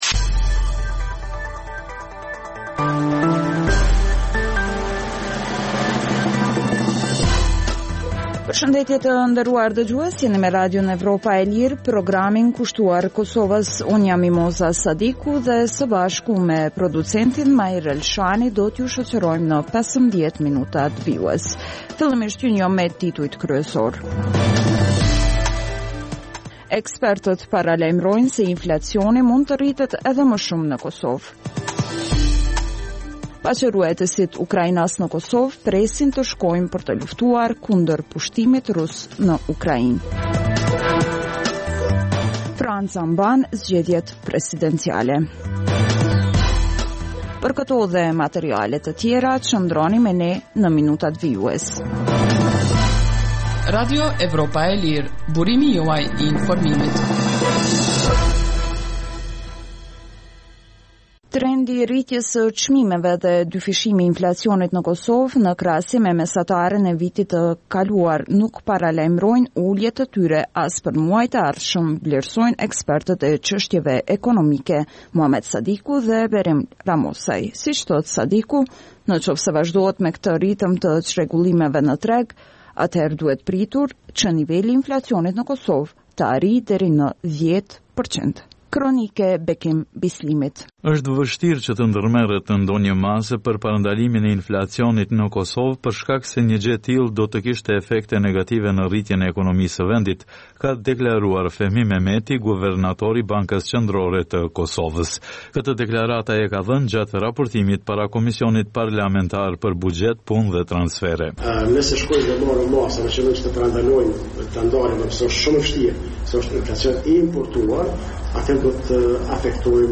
Emisioni i mesditës fillon me buletinin e lajmeve që kanë të bëjnë me zhvillimet e fundit në Kosovë, rajon dhe botë. Në këtë emision sjellim raporte dhe kronika të ditës, por edhe tema aktuale nga zhvillimet politike dhe ekonomike. Emisioni i mesditës në të shumtën e rasteve sjellë artikuj nga shtypi perendimor, por edhe intervista me analistë të njohur ndërkombëtar kushtuar zhvillimeve në Kosovë dhe më gjërë.